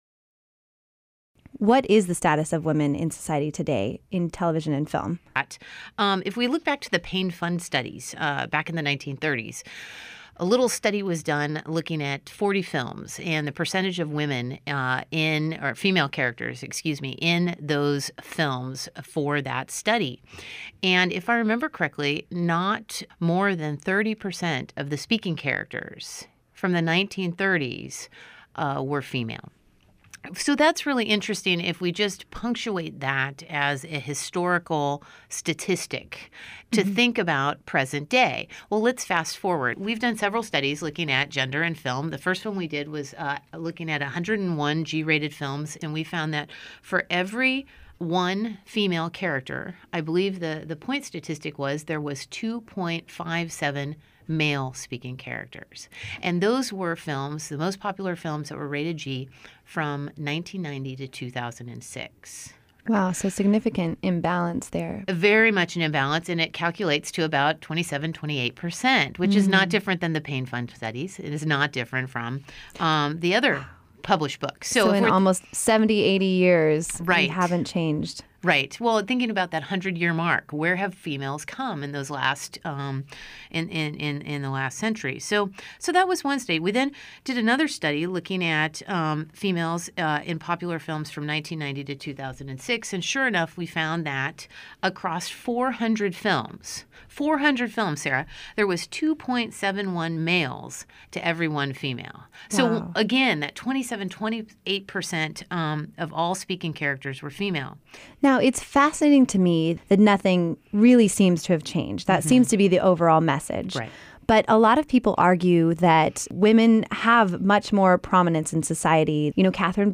HOSTINTERVIEWWOMEN.MEDIA_.mp3